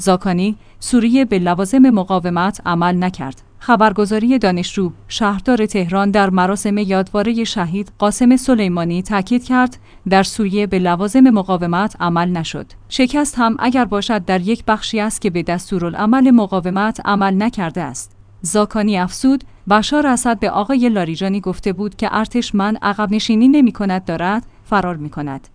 خبرگزاری دانشجو/ شهردار تهران در مراسم یادواره شهید قاسم سلیمانی تأکید کرد: در سوریه به لوازم مقاومت عمل نشد. شکست هم اگر باشد در یک بخشی است که به دستورالعمل مقاومت عمل نکرده است.